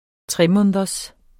Udtale [ ˈtʁεˌmɔːnəðʌs ]